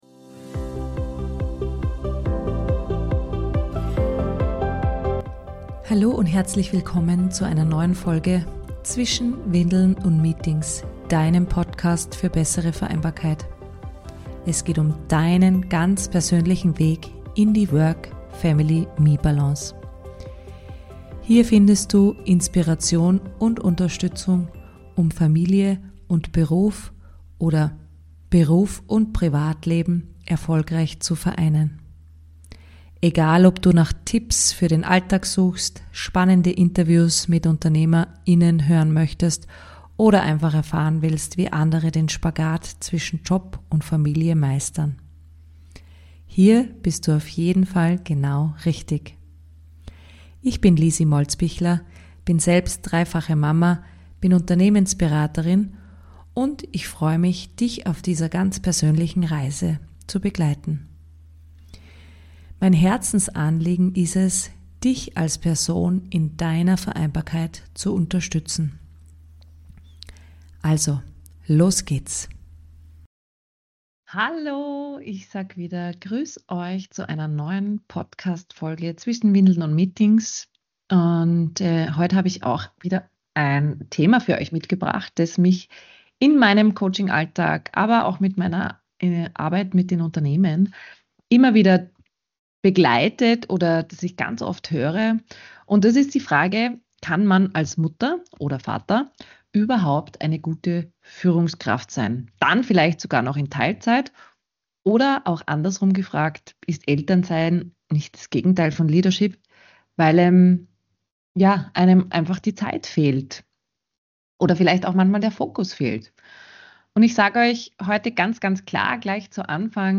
In dieser Solo-Folge teile ich mit dir meine ganz persönliche Reise – und warum Eltern aus meiner Sicht oft die besseren Führungskräfte sind.